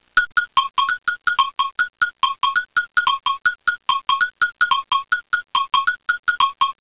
Cowbell
Cowbell.wav